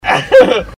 Laugh 8